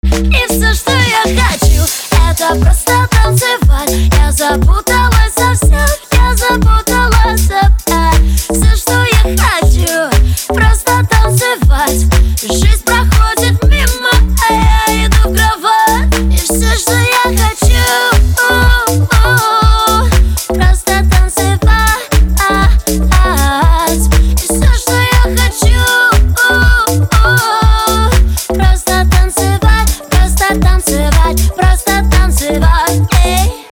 • Качество: 320, Stereo
позитивные
ритмичные
громкие
женский вокал
house